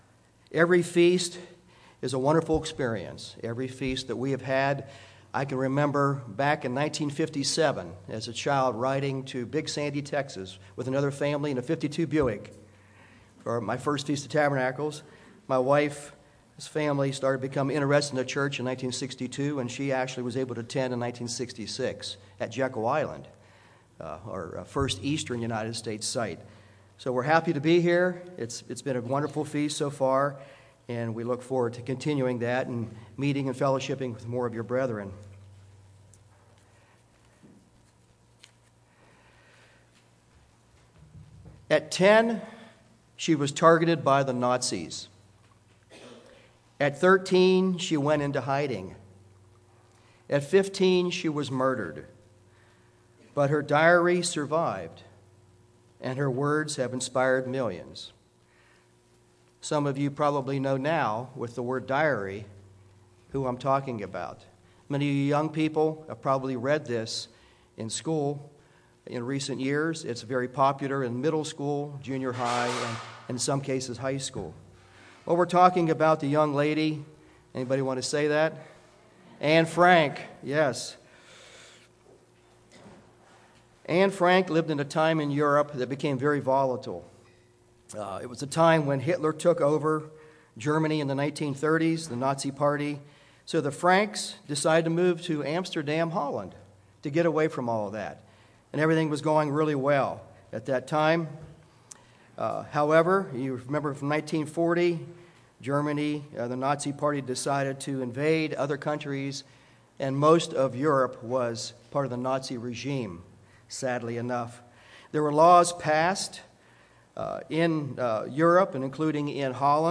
This sermon was given at the Wisconsin Dells, Wisconsin 2019 Feast site.